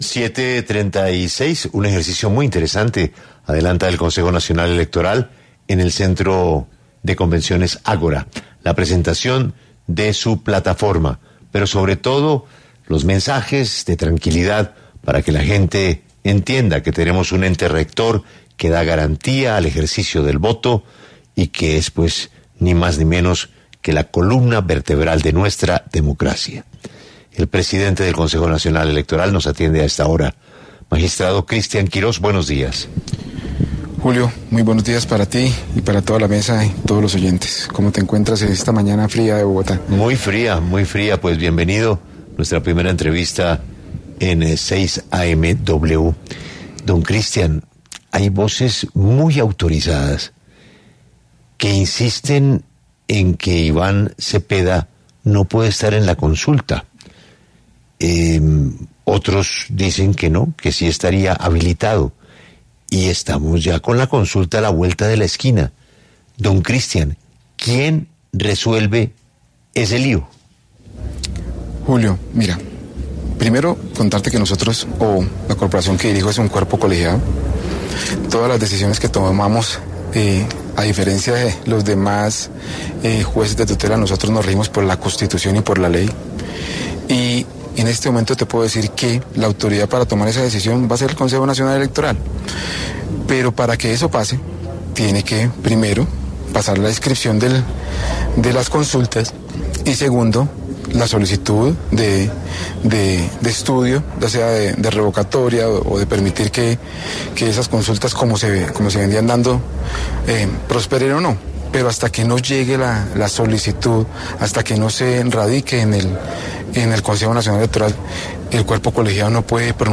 El presidente del Consejo Nacional Electoral, Cristian Quiroz, explicó cómo funcionará esta plataforma en los micrófonos de Caracol Radio.